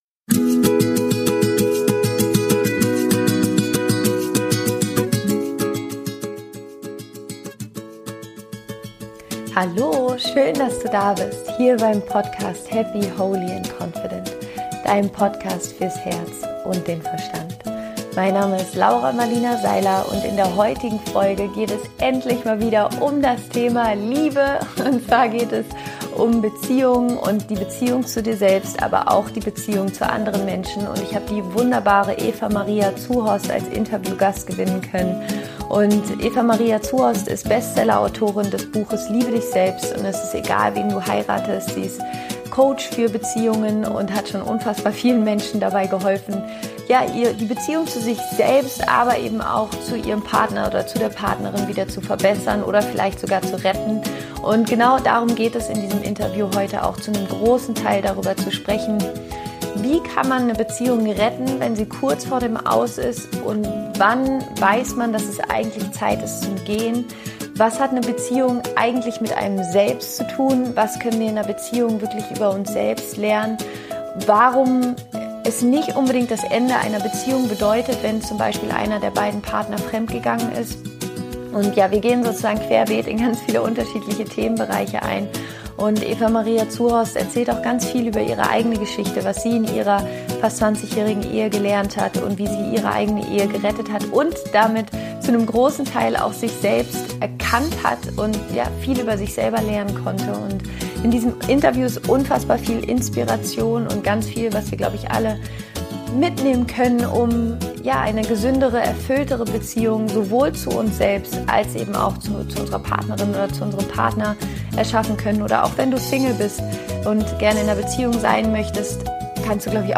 Wie du eine erfüllte Beziehung zu dir selbst und deinem Partner erschaffst - Interview